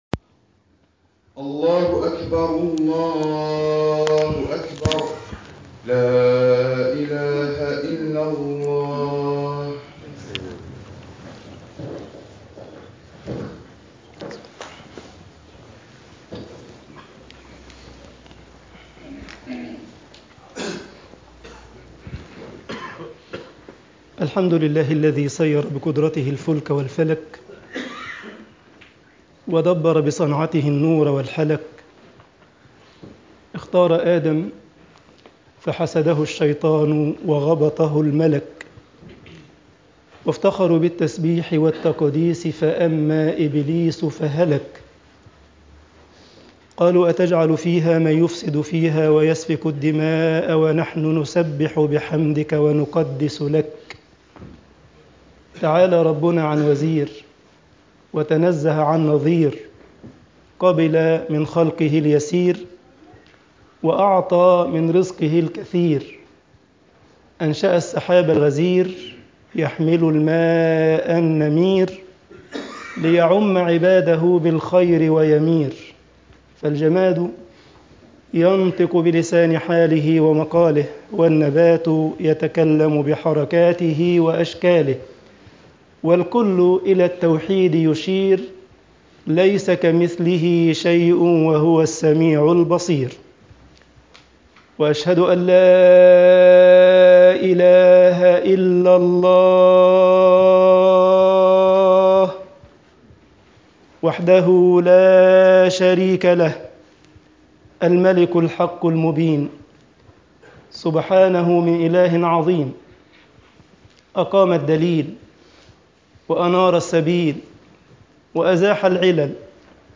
Freitagsgebet_al esmat min fetnat al-ilm10.mp3